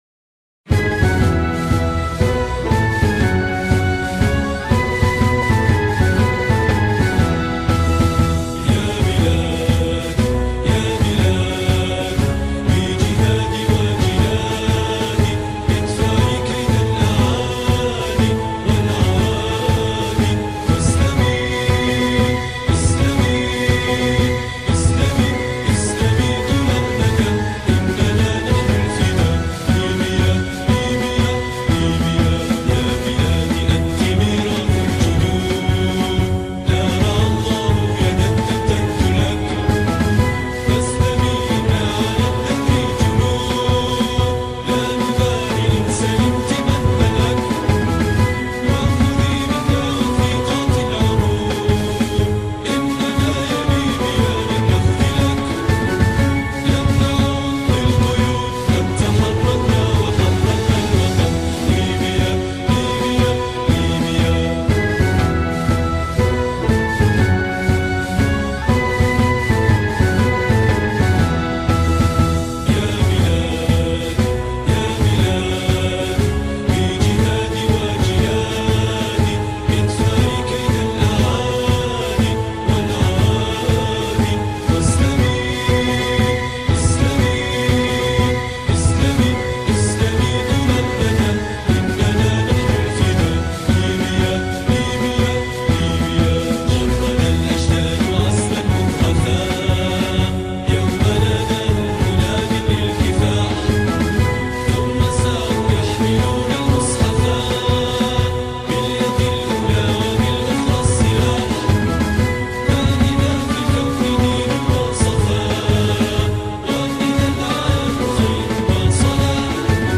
Вокальное исполнение